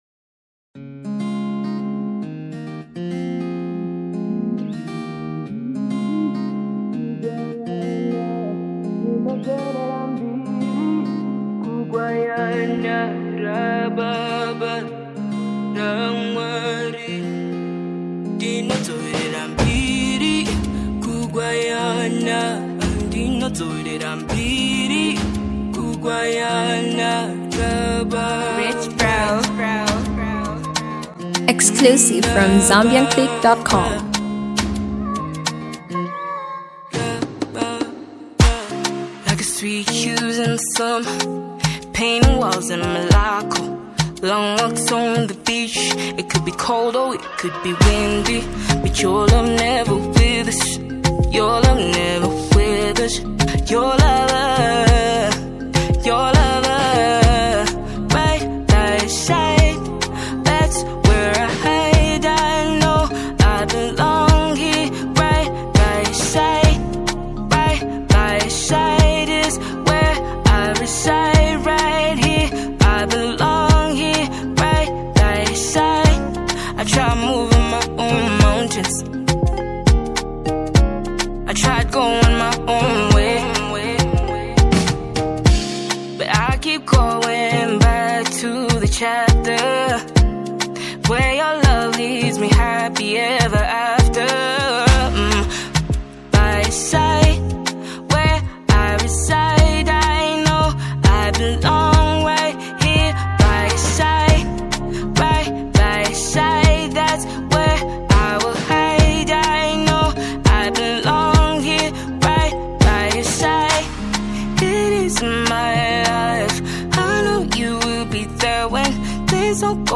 South African songstress